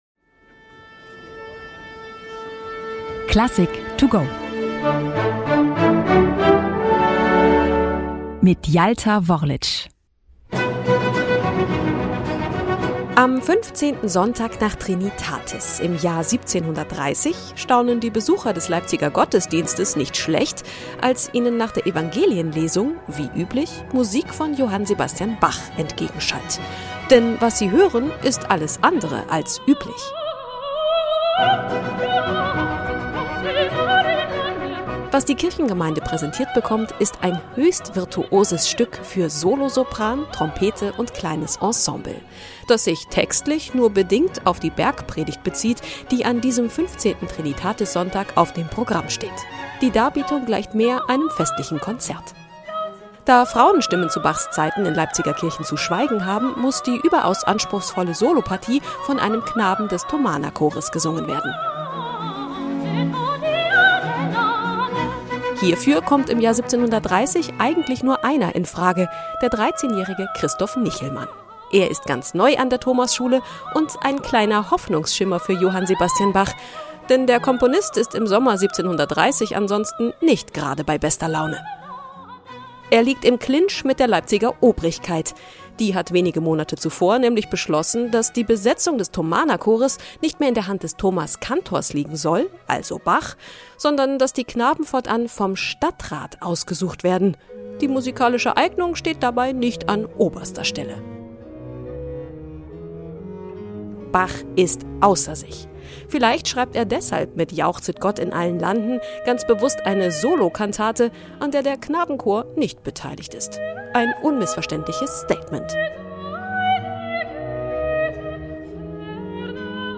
vorgestellt in der Werkeinführung für unterwegs